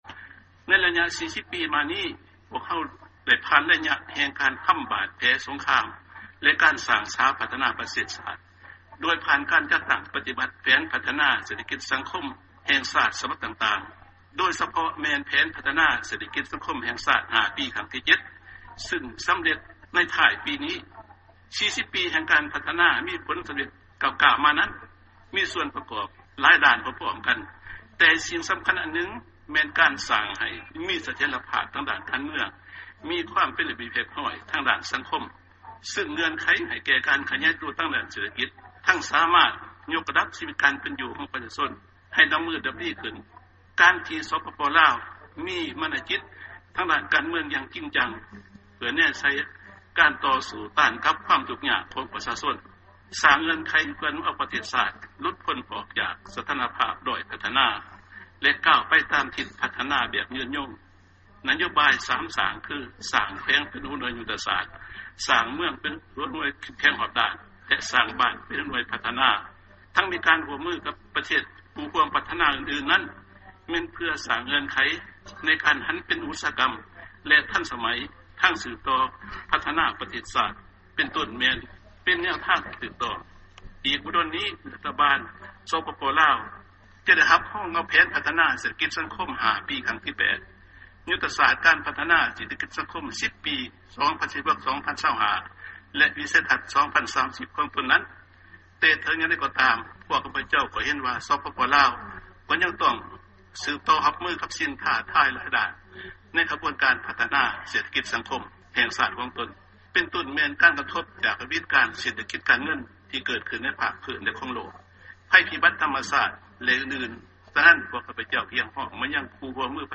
ຟັງຖະແຫບງການ ປະທານປະເທດ ຈູມມະລີ ໄຊຍະສອນ 7